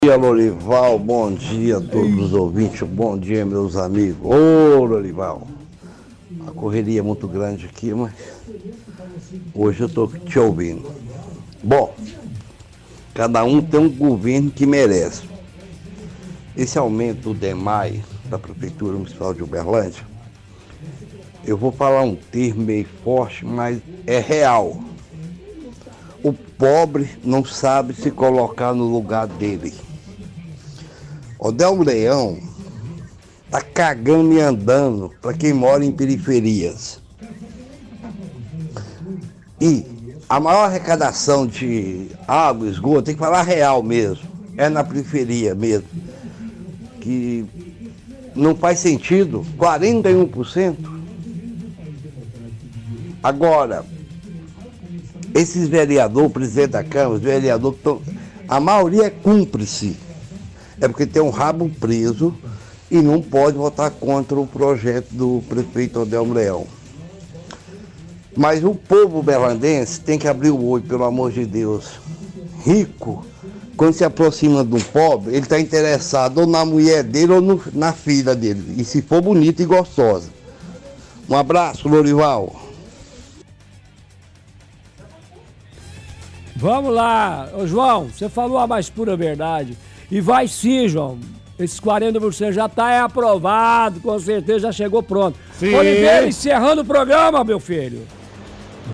– Ouvinte reclama do aumento do Dmae da conta de água, ele diz que o prefeito Odelmo Leão não está nem aí para população da periferia de Uberlândia. Fala que povo da cidade tem que abrir os olhos para quem coloca na política.